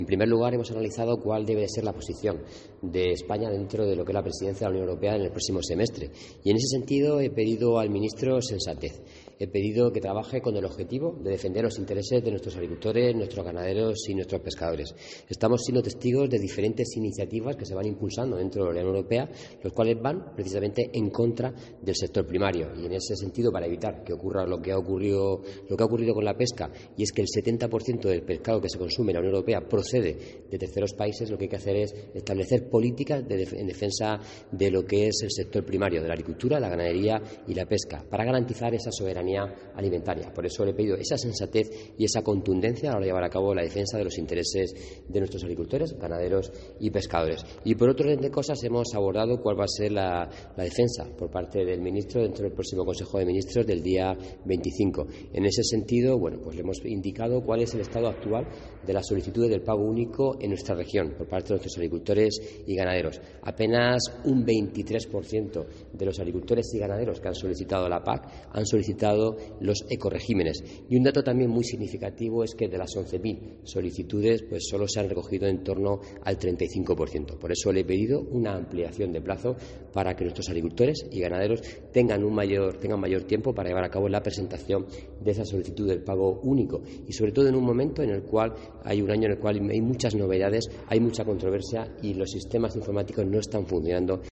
Antonio Luengo, consejero de Agua, Agricultura, Ganadería y Pesca
En declaraciones a los periodistas durante la celebración de los Consejos Consultivos de Política Agrícola y de Política Pesquera para Asuntos Comunitarios, Luengo ha pedido "sensatez" al ministro de Agricultura, Pesca y Alimentación, Luis Planas, en la presidencia española de la Unión Europea (UE) en el segundo semestre del año.